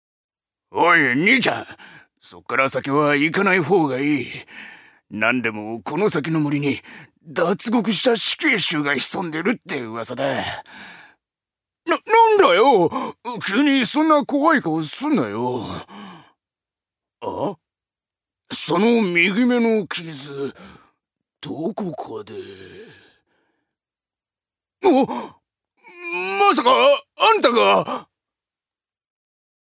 所属：男性タレント
音声サンプル
セリフ５